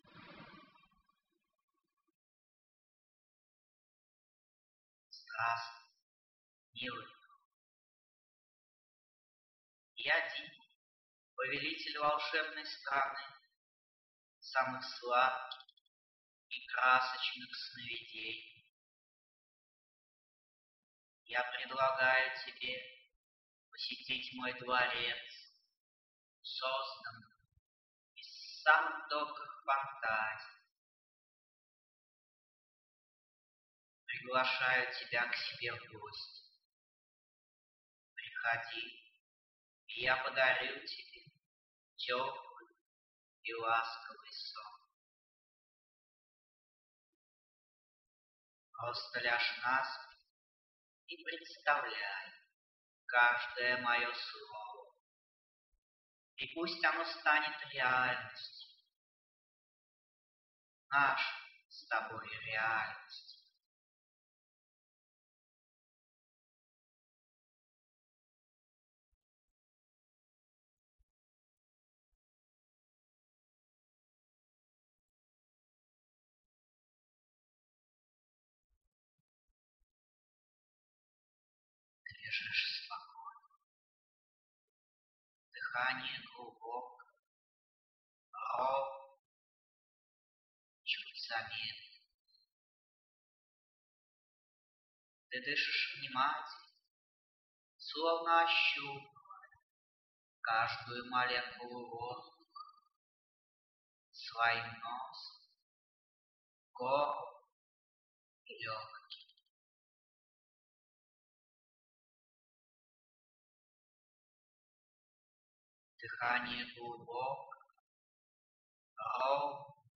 Медитация хорошего настроения
Музыка для медитации